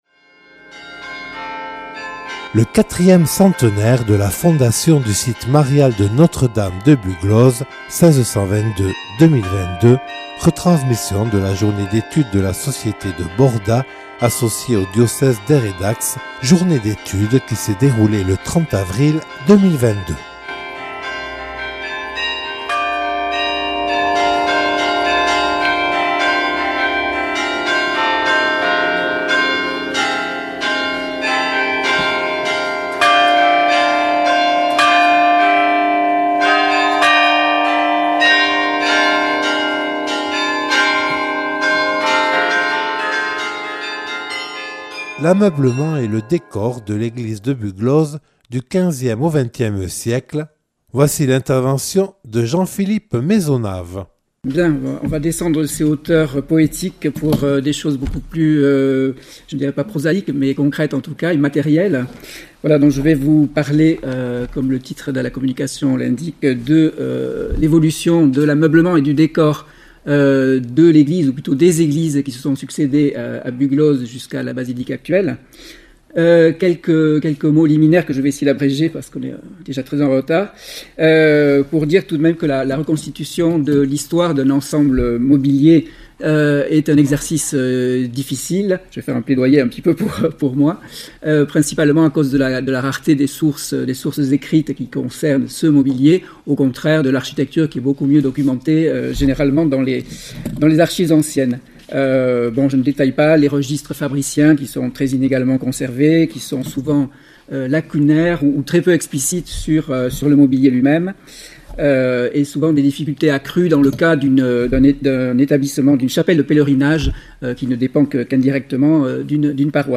Retransmission de journée d’études de la société de Borda associée au diocèse d’Aire et Dax le 30 avril 2022